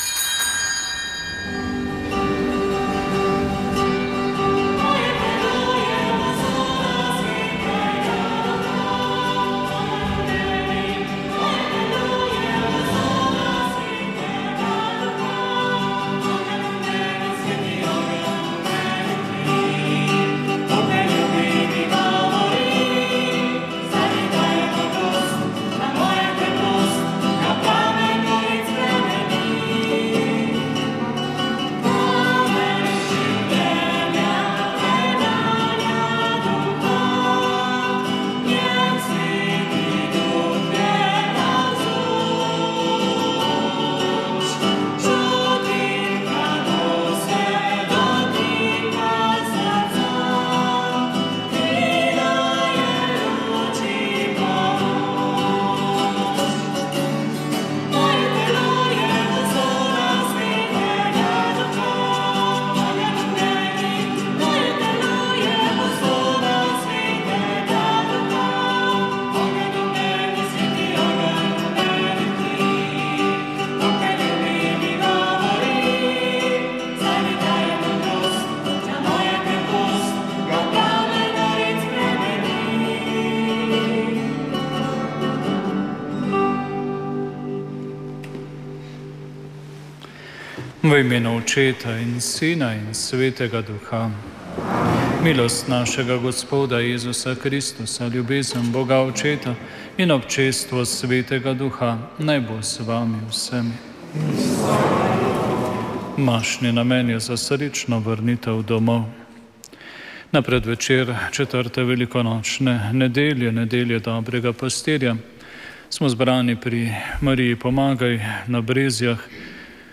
Sveta maša
Sv. maša iz cerkve Marijinega oznanjenja na Tromostovju v Ljubljani 26. 3.